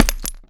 grenade_hit_concrete_hvy_02.WAV